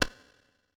PixelPerfectionCE/assets/minecraft/sounds/item/shovel/flatten1.ogg at mc116